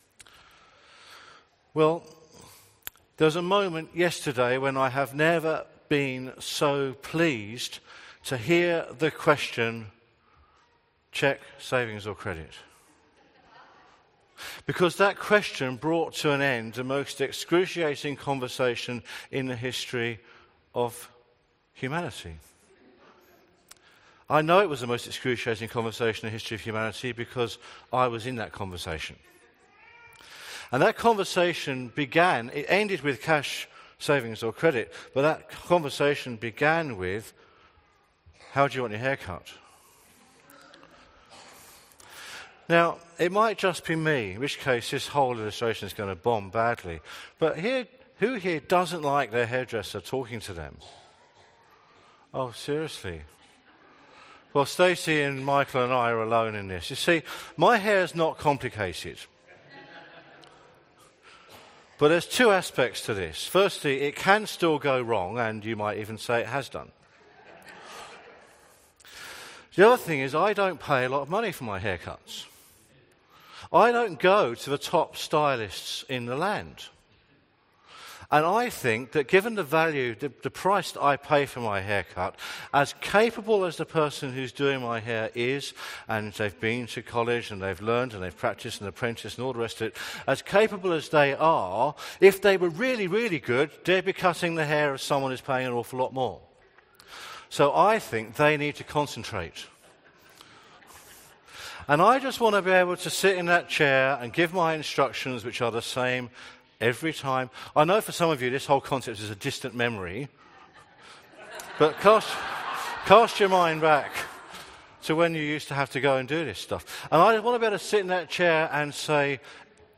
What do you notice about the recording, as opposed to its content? Sermon with Bible reading from the 10AM meeting on Sunday 19/02/2017 at Newcastle Worship & Community Centre of The Salvation Army. The Bible reading was taken from Psalm 119:33-40.